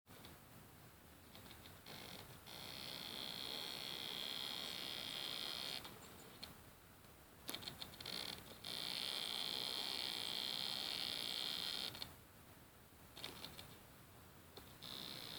Quietschen und Krachen beim fokussieren - ValueTech-Community-Forum
Das kratzende Geräusch tritt nur im beim Filmen auf, das Quietschen jeder Zeit.